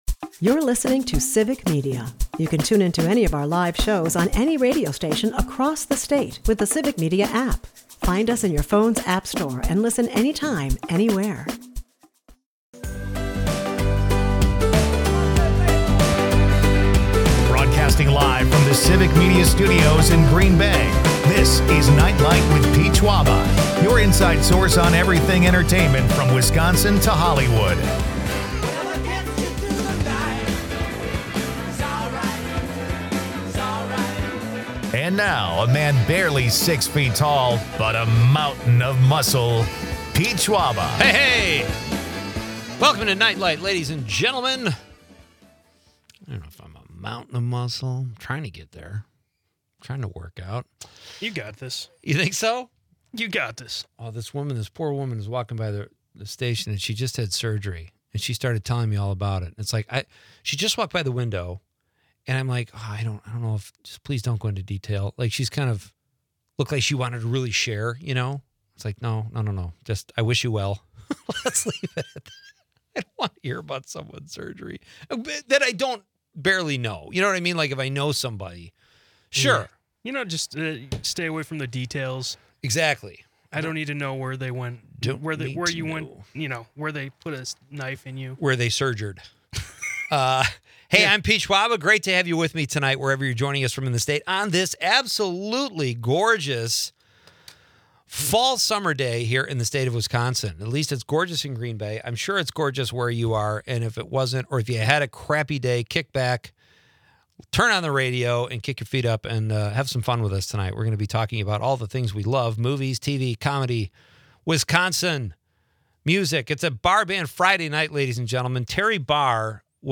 The night wraps with a fun musical debate, and a surprise call-in critiques some classic rock bands.